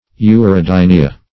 urodynia.mp3